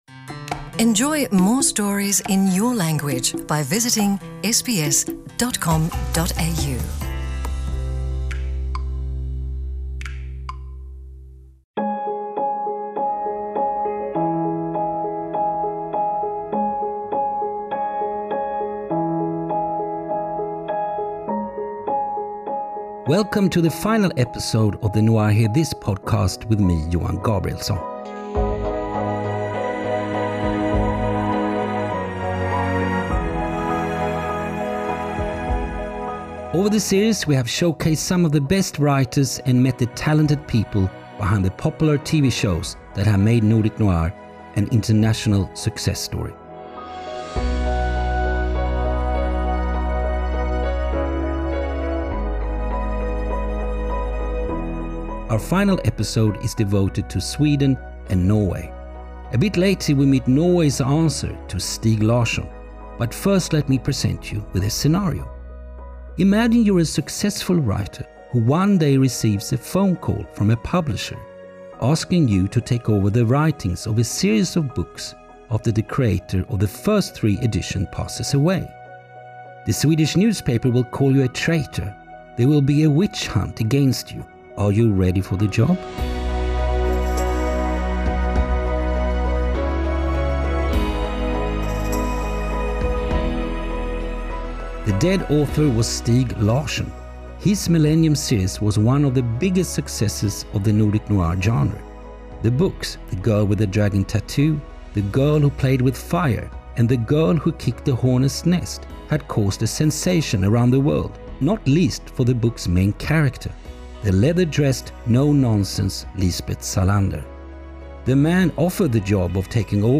We meet David Lagercrantz who took on Larsson's legacy and continued the Millennium series with The Girl In The Spider’s Web .